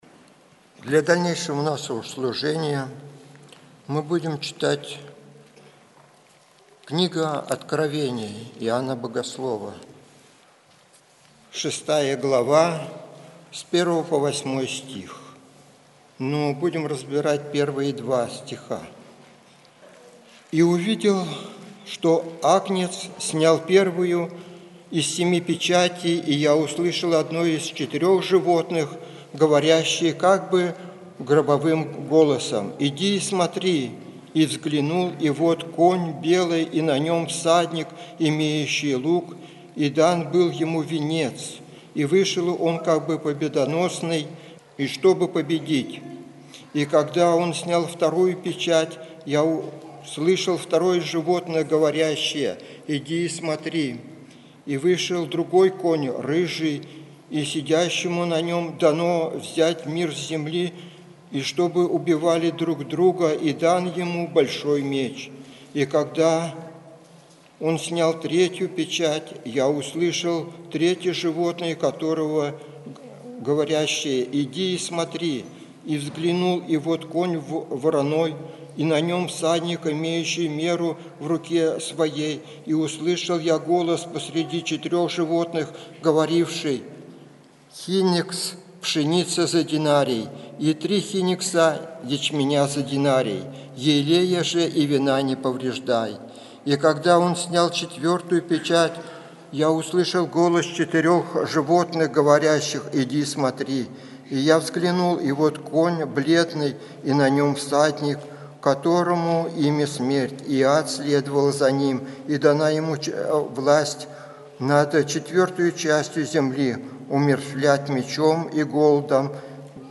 Откровение 6:1-8. Проповедует
Церковь евангельских христиан баптистов в городе Слуцке